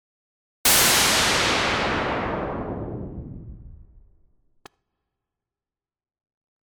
逆に、カーブを逆にすると、今度は下降していく音になります。
こんな感じでプシューーーーーーーみたいな音になります。